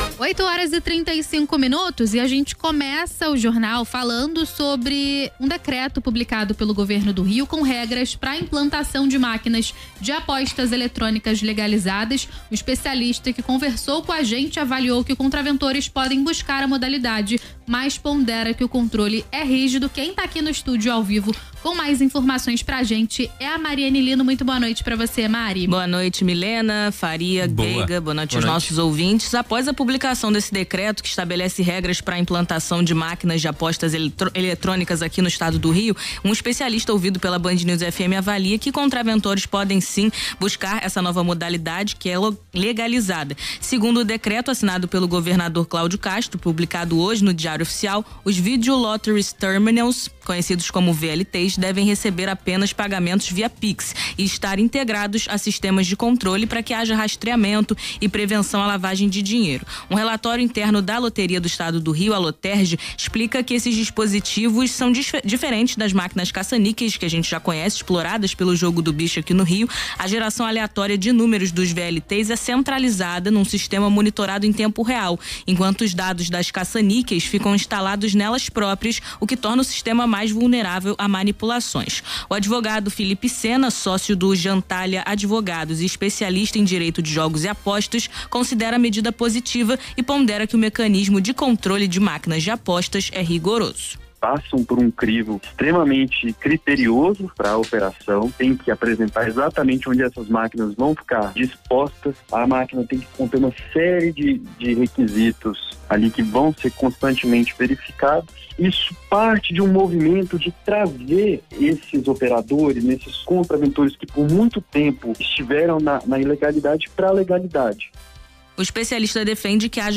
Rádio